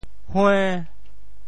“横”字用潮州话怎么说？
hue~5.mp3